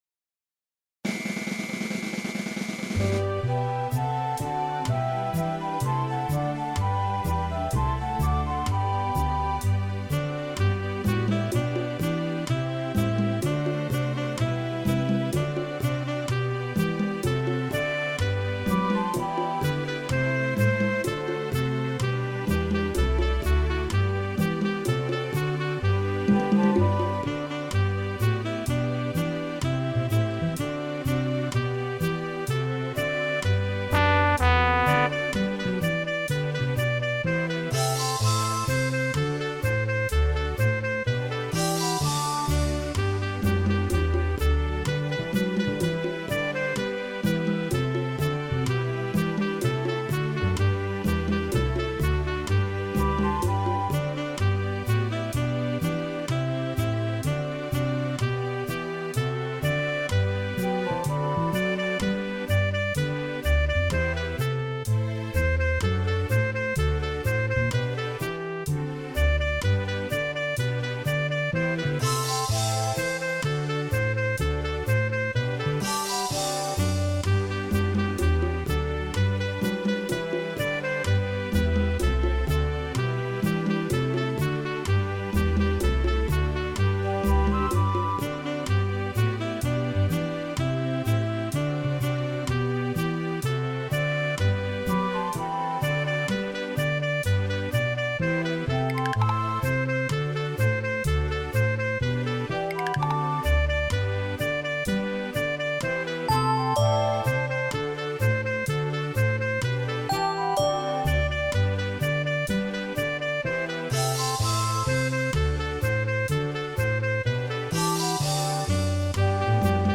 gerealiseerd op de Roland Fantom 08